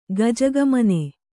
♪ gajagamane